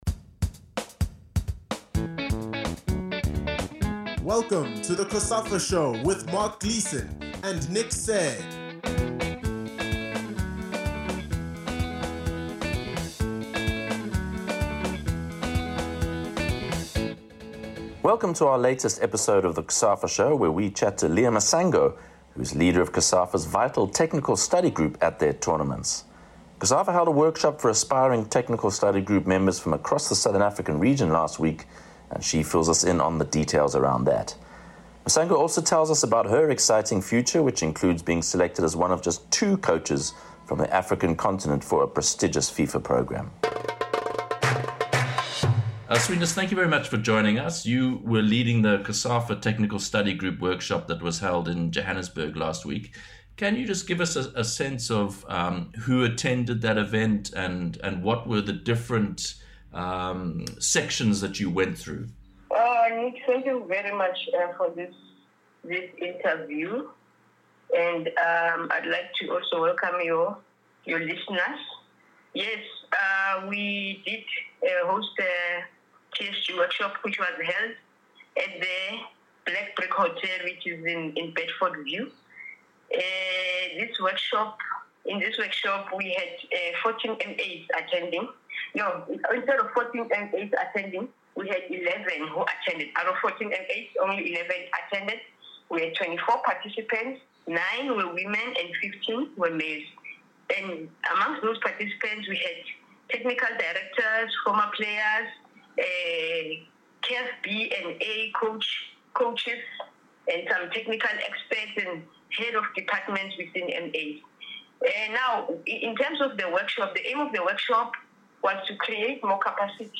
This in-depth interviews covers areas such as the senior national teams, women’s football, junior set-ups, the local league, referees and much, much more in order to get a solid understanding of the current state of Lesotho football, but also what the future holds.